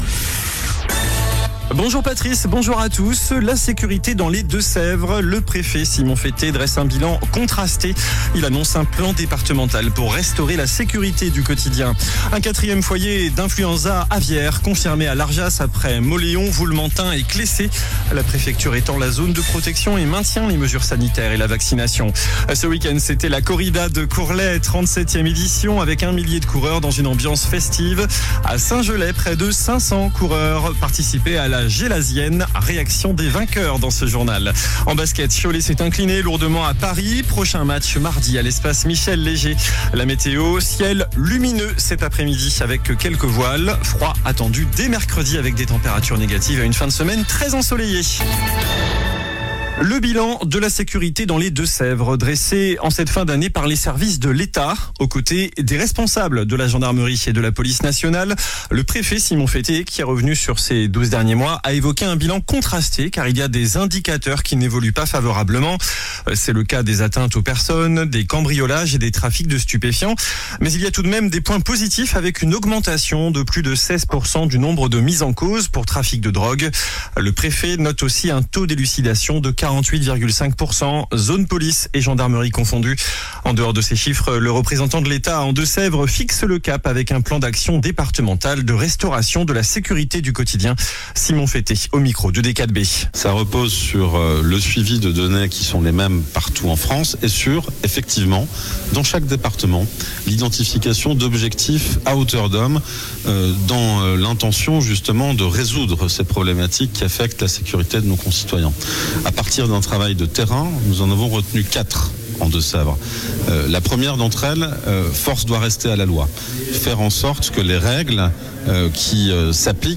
JOURNAL DU LUNDI 22 DECEMBRE ( MIDI )